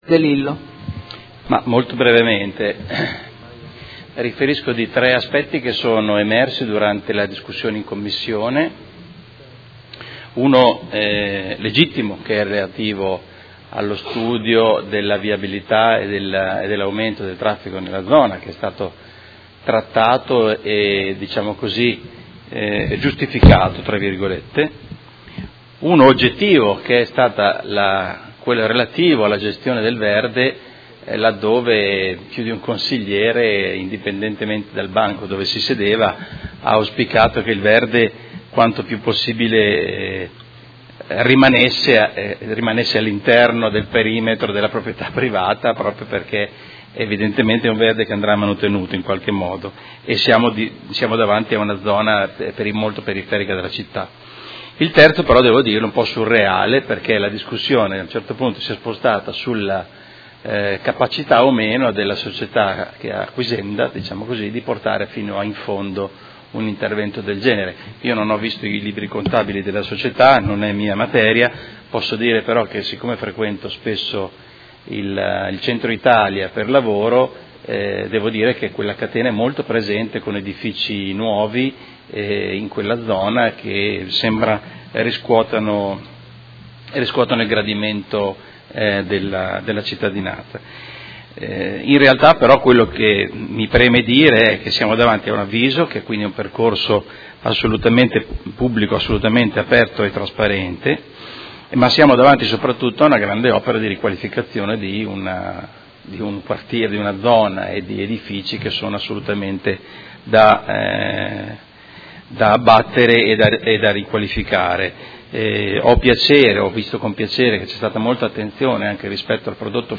Seduta del 28/03/2019.